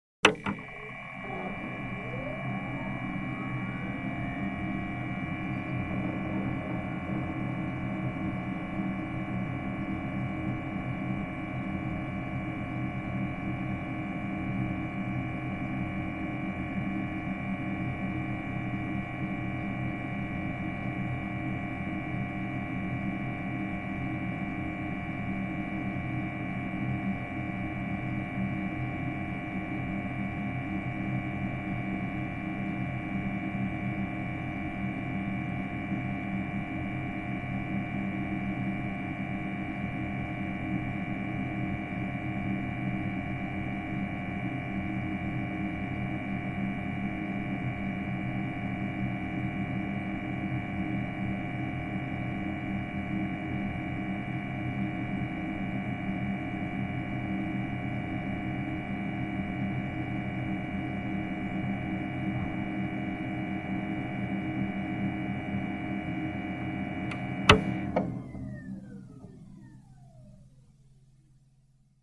d0 gentle Computer hum with hard drive access
描述：A gentle looping atmo of a computer with fans, a very silent transformer hum and occasional hard drive access.
标签： loop disk harddisk harddrive fan computer fans PC access drive harddisk hum atmo noise harddrive
声道立体声